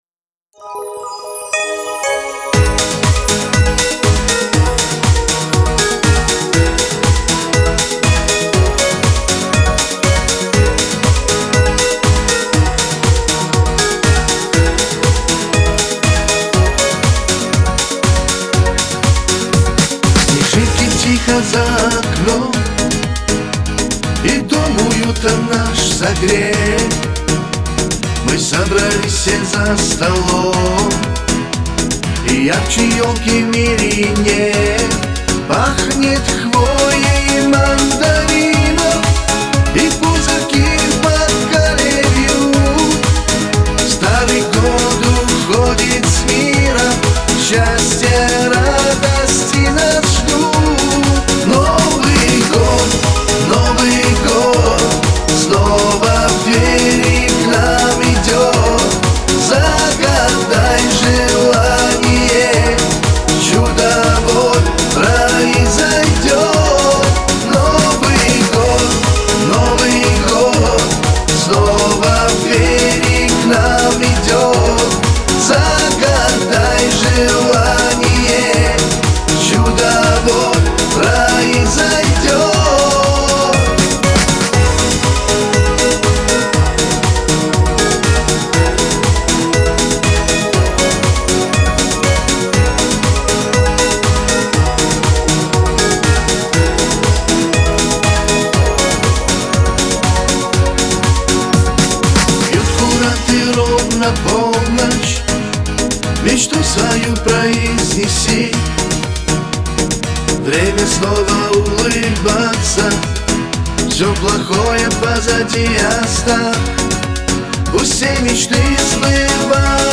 Кавказ поп